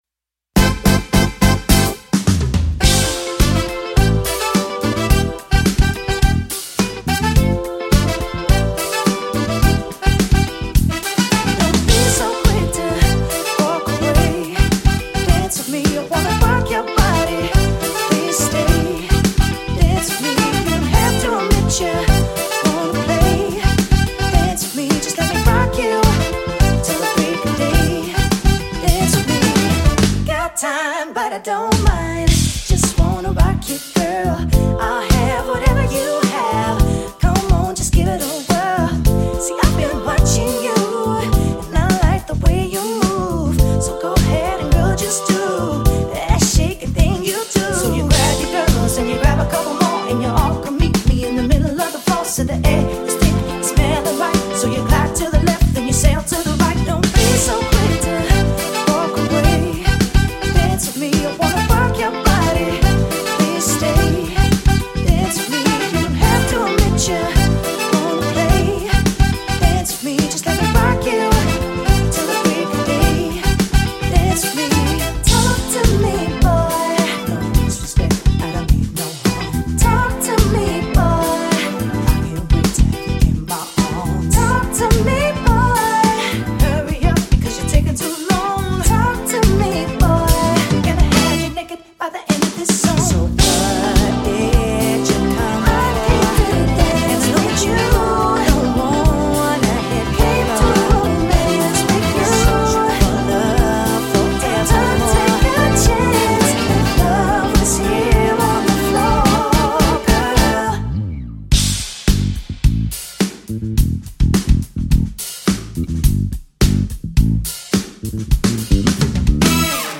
repertoire samples